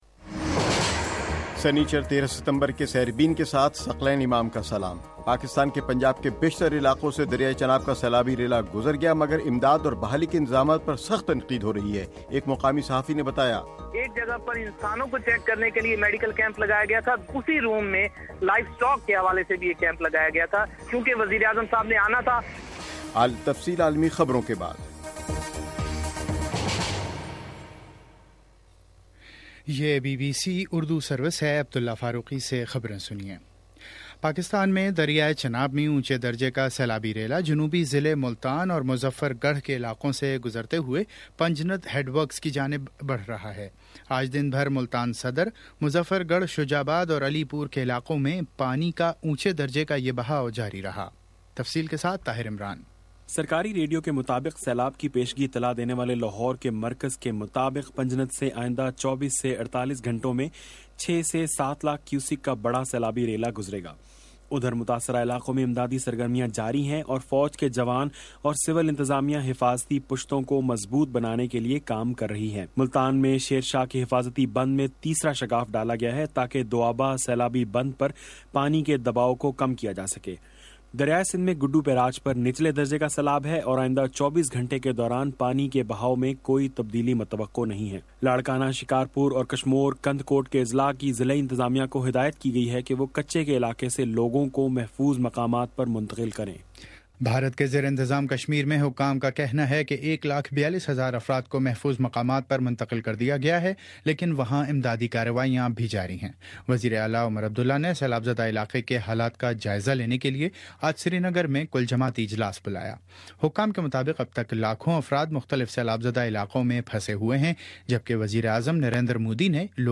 سنیچر 13 ستمبر کا سیربین ریڈیو پروگرام